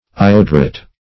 ioduret - definition of ioduret - synonyms, pronunciation, spelling from Free Dictionary Search Result for " ioduret" : The Collaborative International Dictionary of English v.0.48: Ioduret \I*od"u*ret\, n. (Chem.) Iodide.